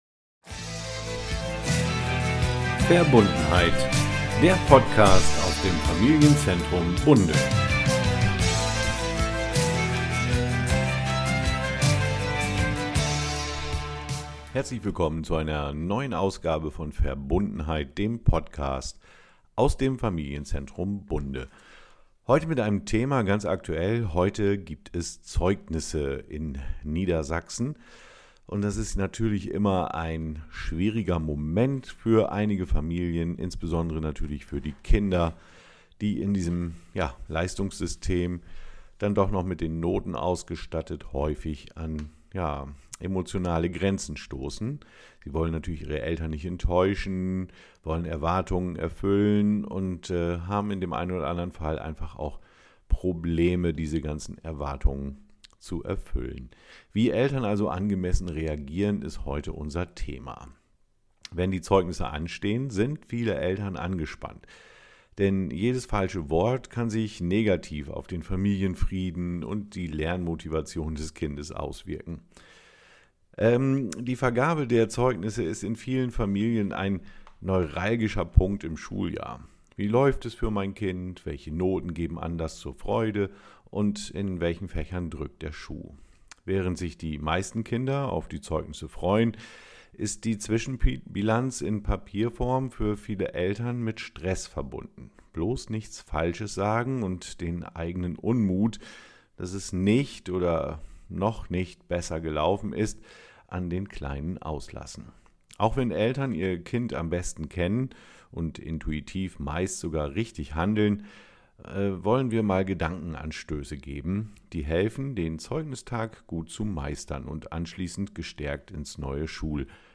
Interview mit den drei Sozialpädagogik-Projektstudentinnen